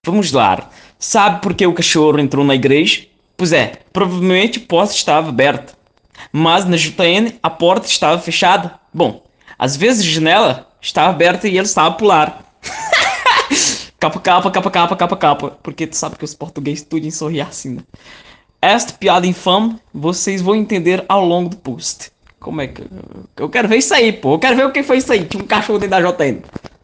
e ainda tem a versão pt-pt